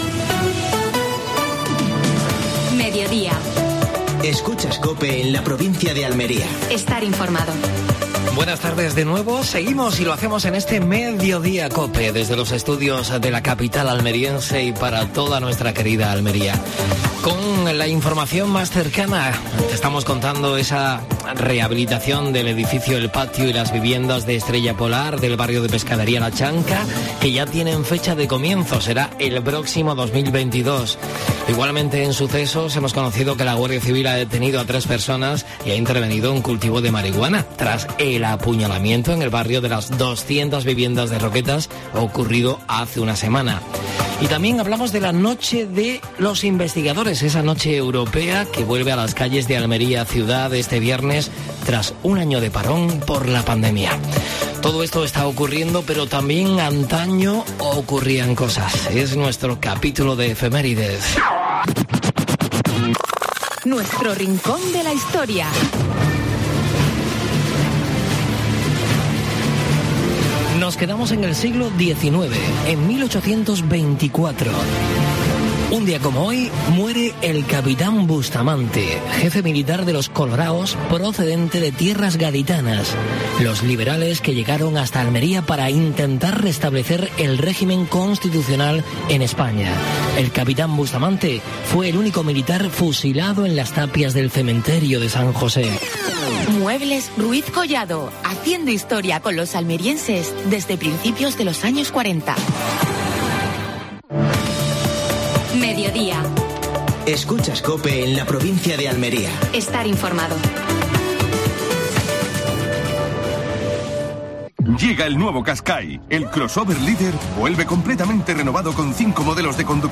Actualidad en Almería. Entrevista a Ismael Torres (alcalde de Huércal de Almería).